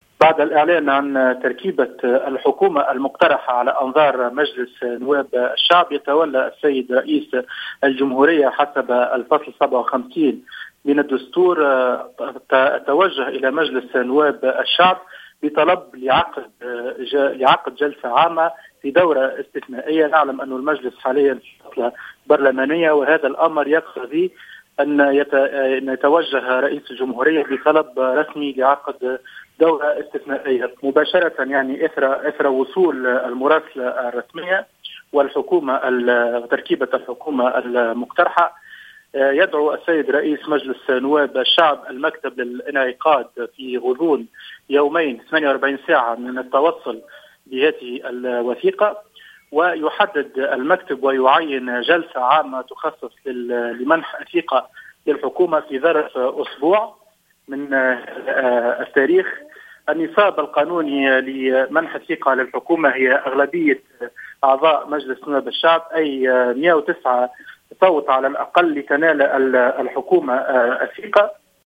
وأوضح في تصريح لـ "الجوهرة أف أم" أن هذه الثقة تقتضي أغلبية الأعضاء وبـ 109 أصوات على الأقل حتى تنال الحكومة ثقة النواب.